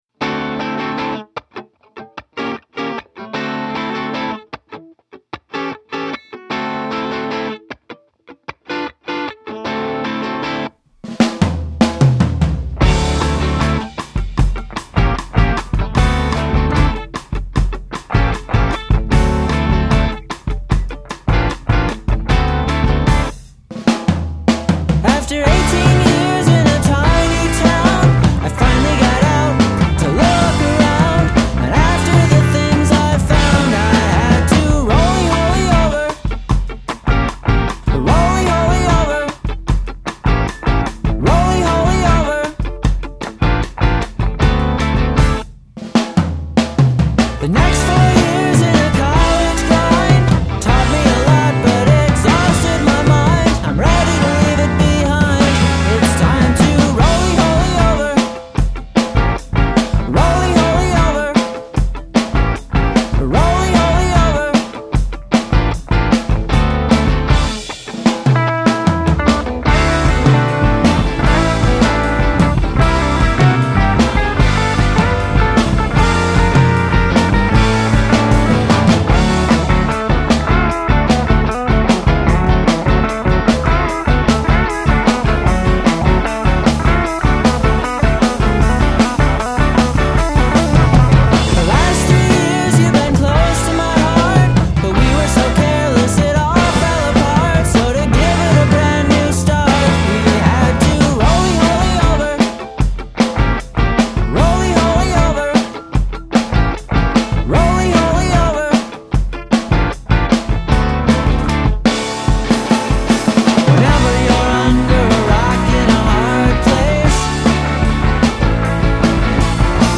vocals, rhythm guitar
lead guitar
bass
drums